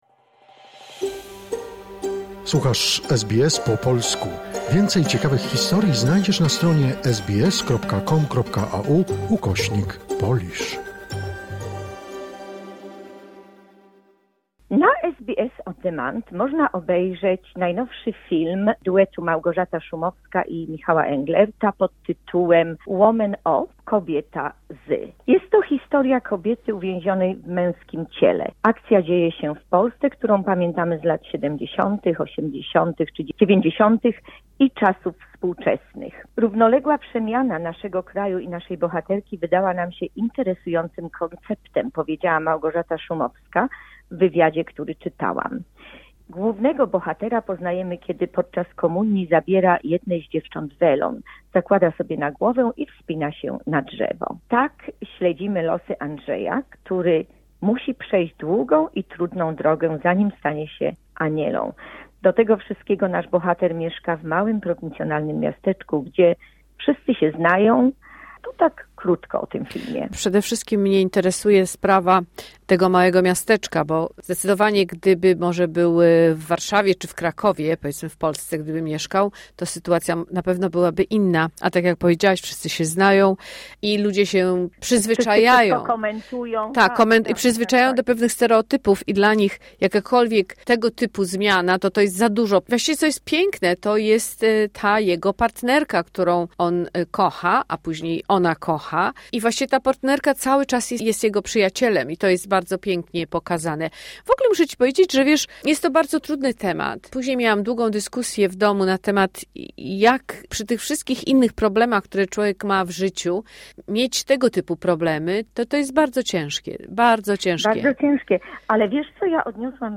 "Woman of..." - recenzja filmowa